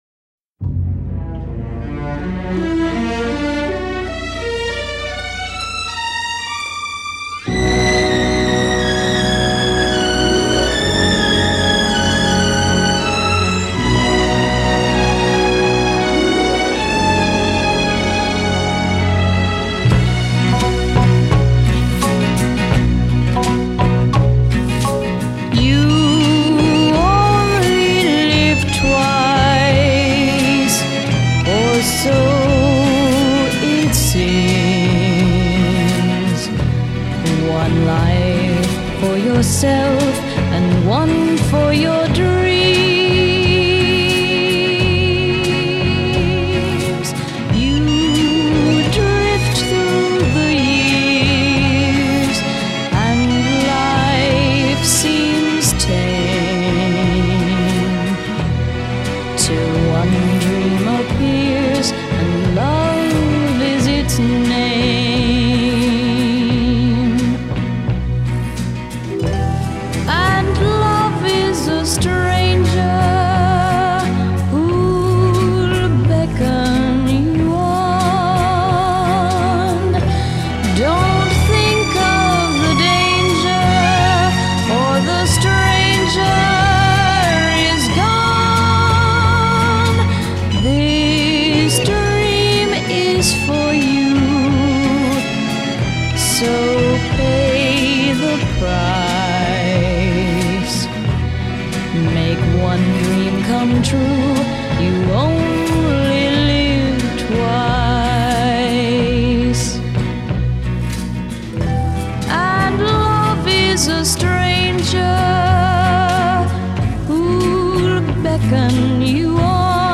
2. There’s a hint of east-Asian influence.
A reluctant diva.
Tags1960s 1967 Eastern US pop soundtrack